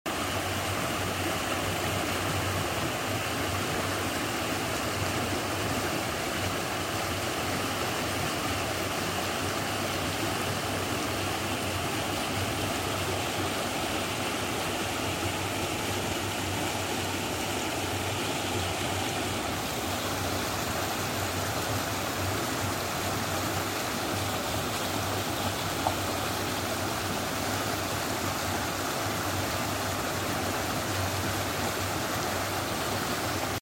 sound of running water sound effects free download